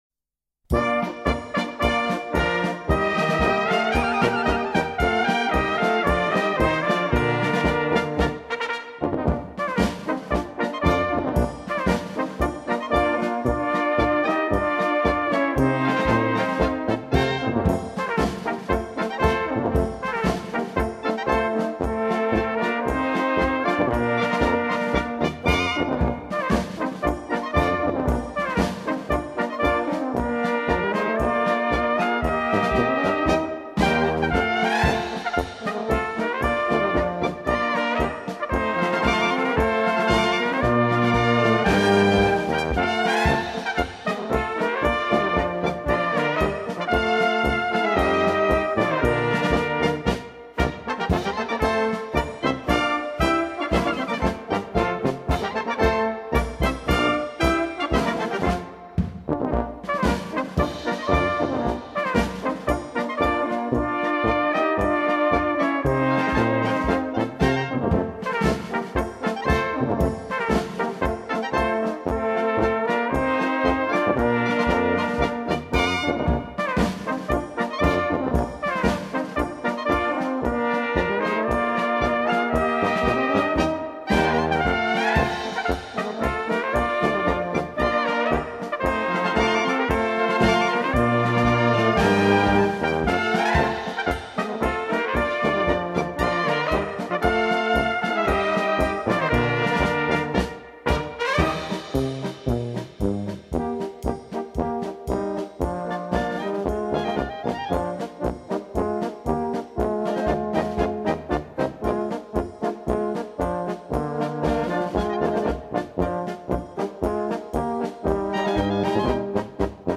Polka, Orchestertitel